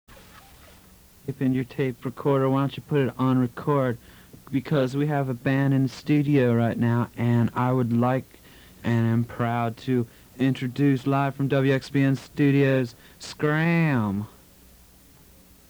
heavy Reggae influence